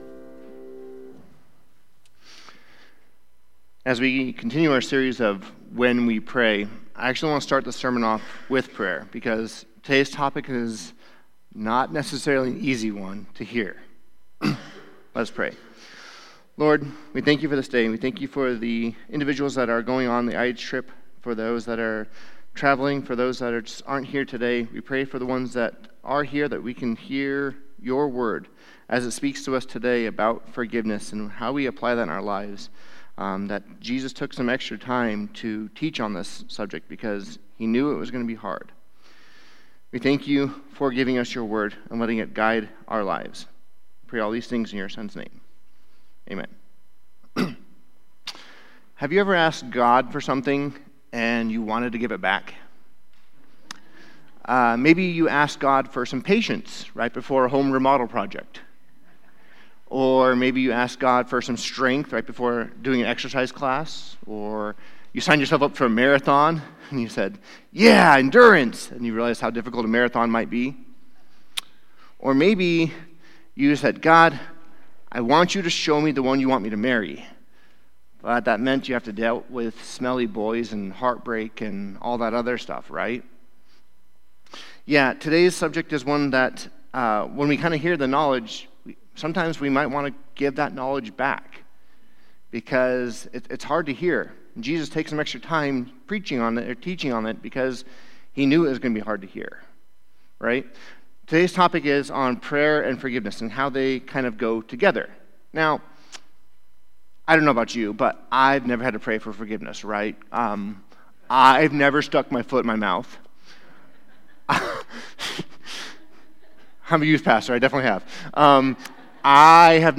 Sermons | Enterprise Christian Church